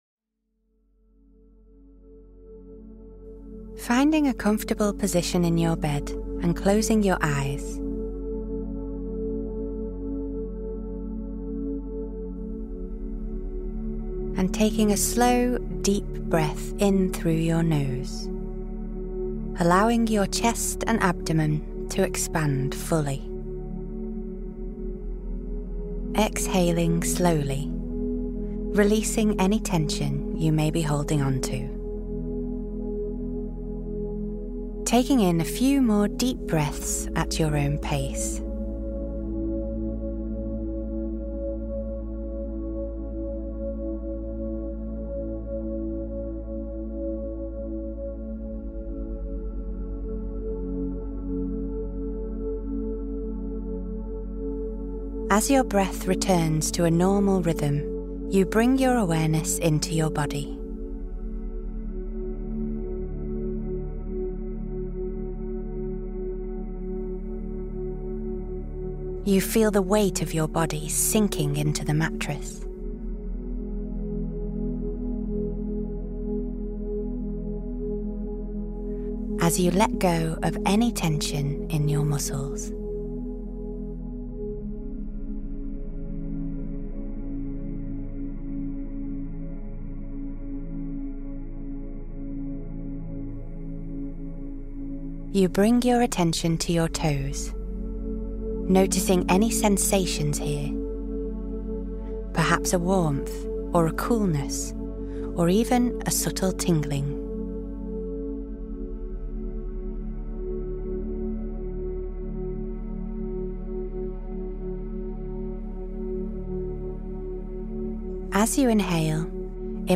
Calm Mind, Restful Body Sleep Meditation — Guided Meditation for Peaceful Sleep